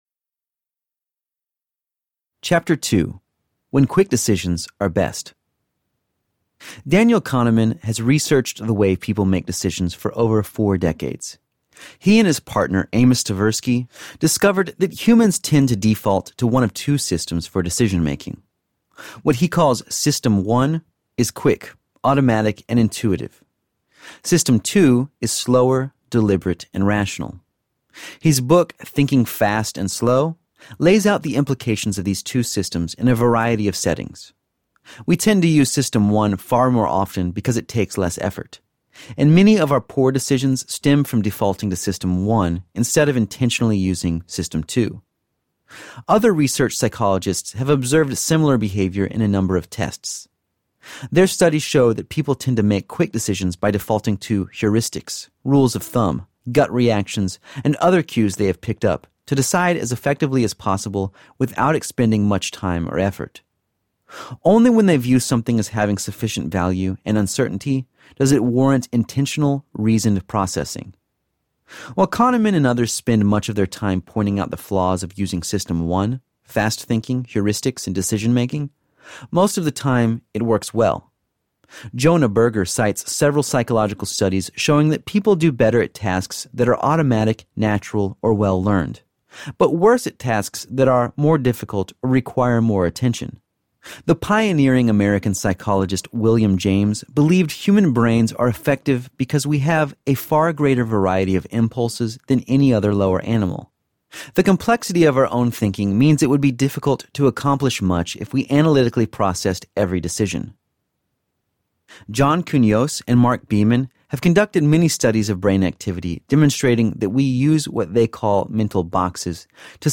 How to Make Big Decisions Wisely Audiobook
Narrator
4.9 Hrs. – Unabridged